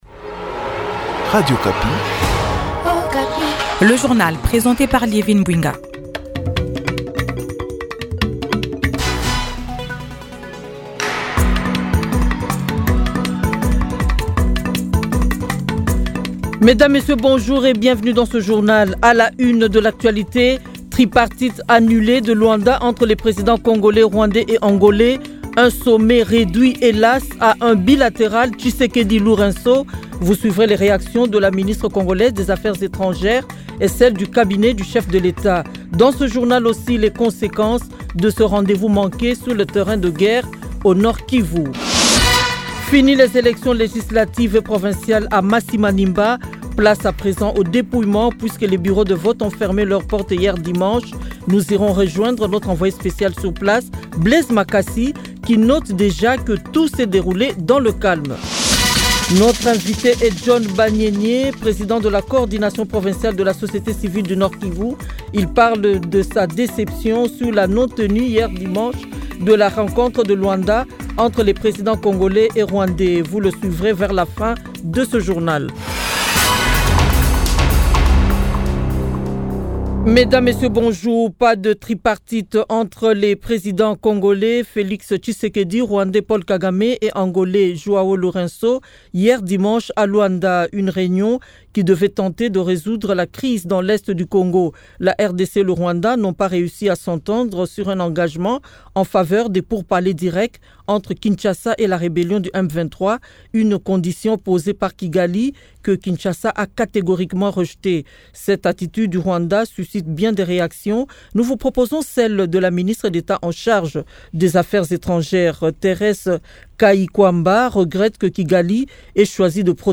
Journal 6h et 7h lundi 16 décembre 2024